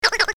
clock08.ogg